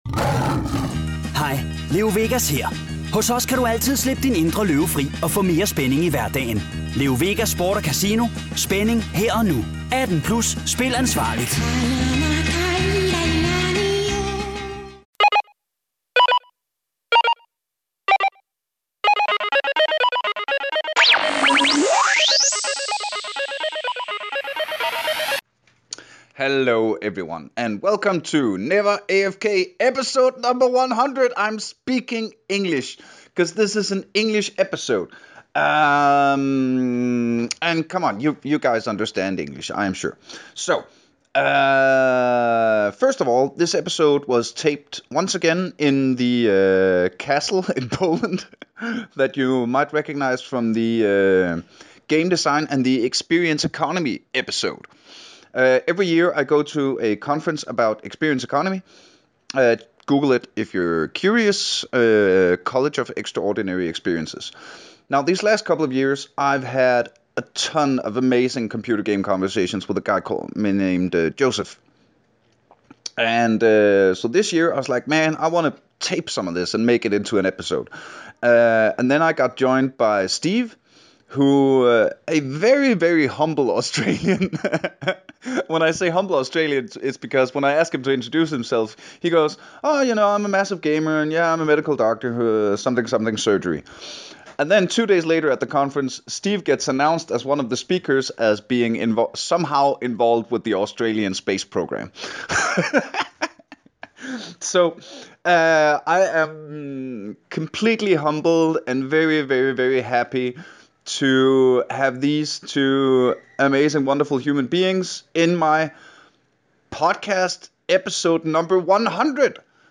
Once again, I have travelled to a castle in Poland to find the best conversations about gaming this world has to offer... I am joined by 2 funny and wonderful gentlemen, who are both massive gamers and way smarter than me!